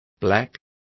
Complete with pronunciation of the translation of blacks.